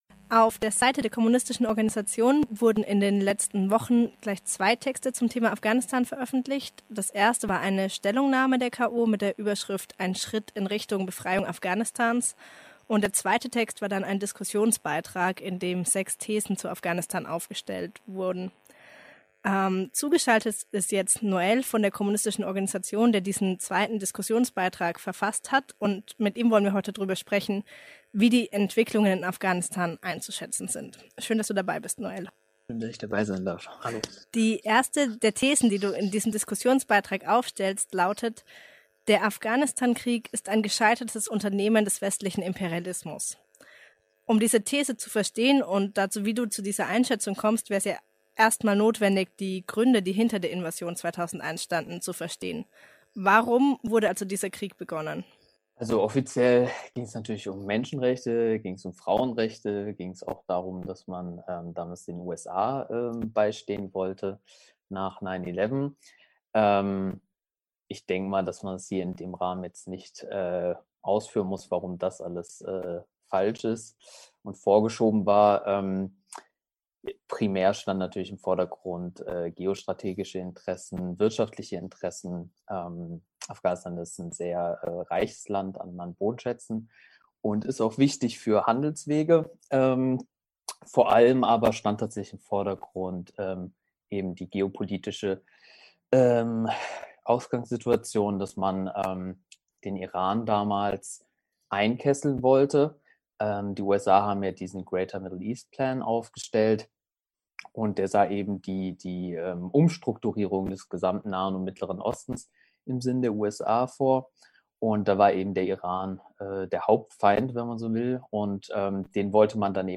Ist der Abzug der westlichen Truppen ein Schritt in Richtung Befreiung? Schwerpunktsendung mit Interviews von Vertretern der Kommunistischen Organisation und der Informationsstelle Militarisierung